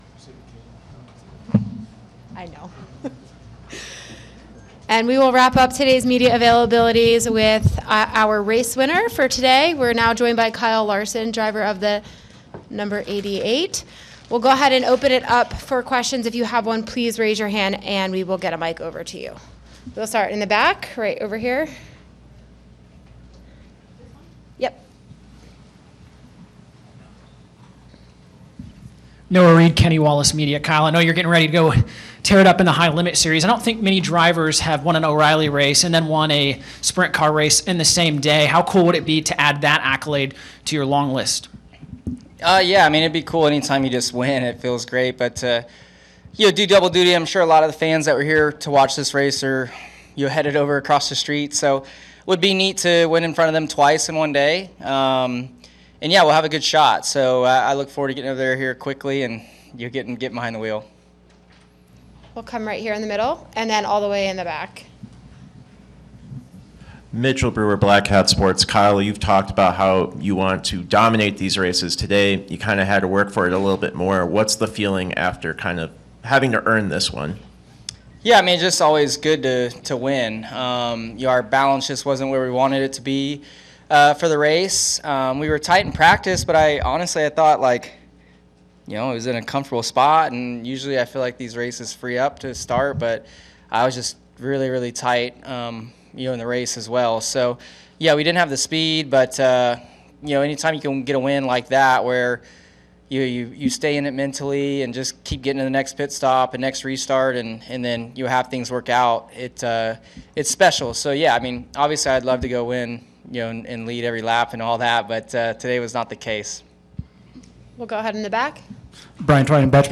Interview: NOAPS Race Winner – Kyle Larson (No. 88 JR Motorsports Chevrolet) –
NASCAR O’Reilly Auto Parts Series Race – The LiUNA